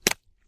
STEPS Pudle, Walk 23.wav